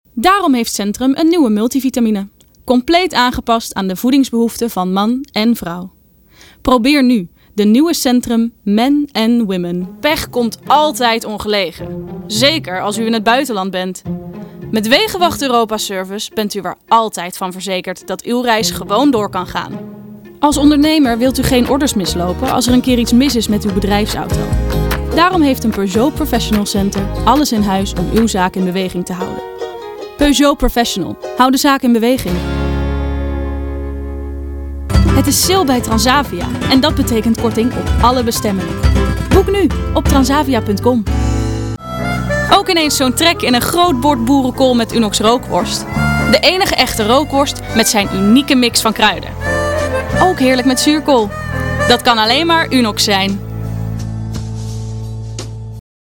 Voice Over Demo Audio